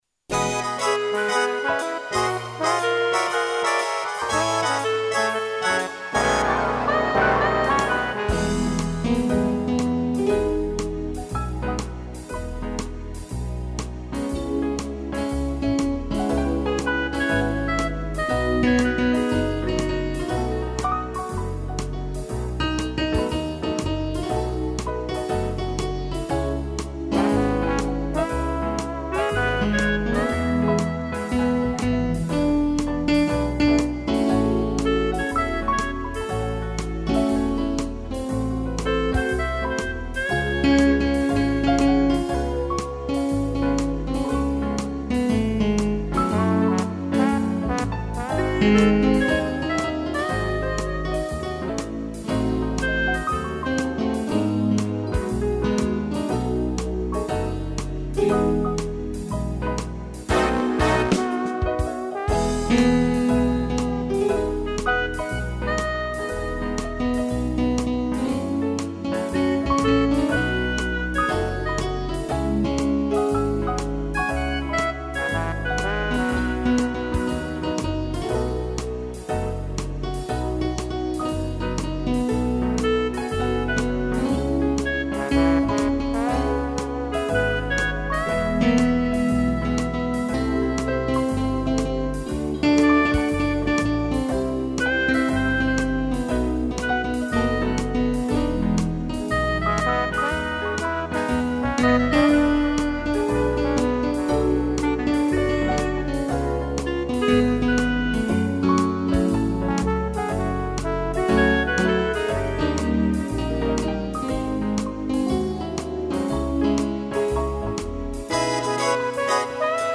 piano, trombone e clarinte
(instrumental)